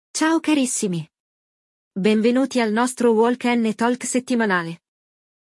Neste episódio, vamos aprender sobre organização e faxina de casa ouvindo uma conversa entre amigos.